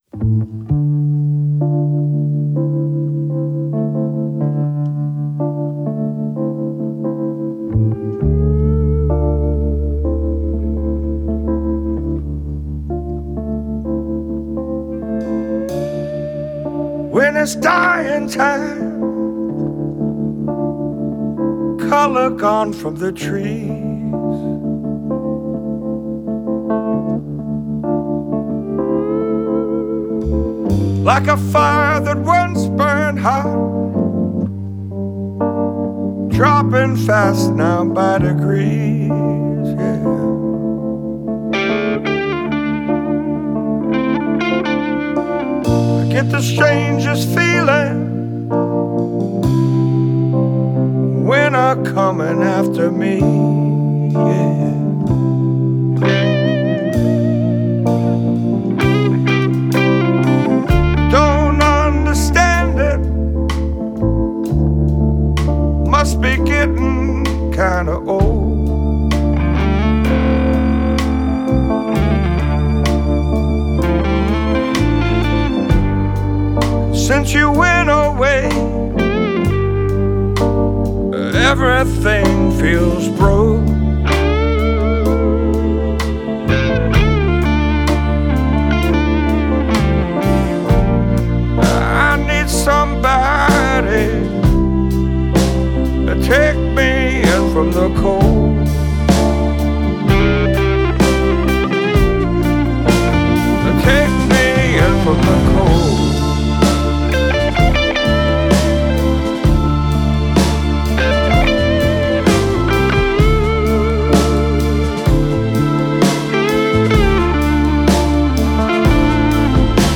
It is a deserving honor for the Atlanta based blues rocker.
intense brand of blues rock